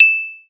ding.wav